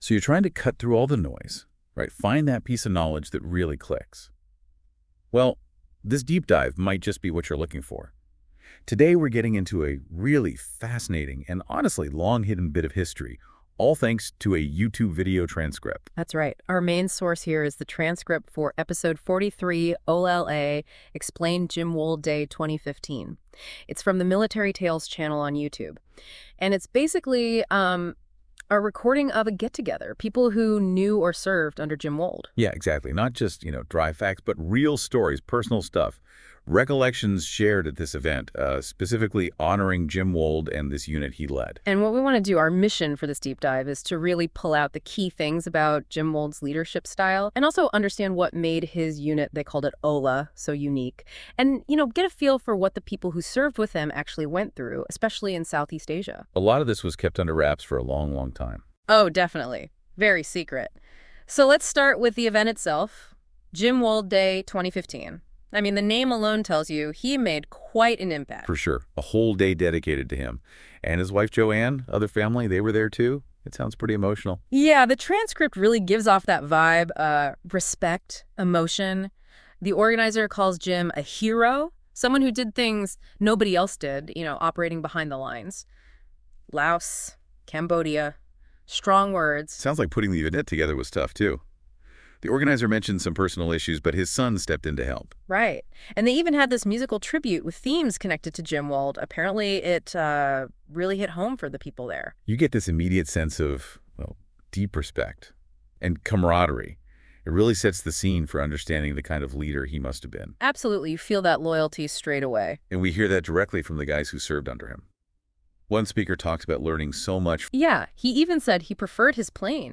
Veterans share vivid accounts of flying demanding close air support and vital Search and Rescue (SAR) missions, often operating dangerously low “in the weeds,” underscoring their reliance on the critical support from their armors and maintenance crews .